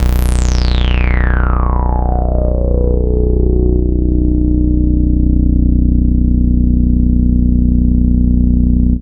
POISON BASS.wav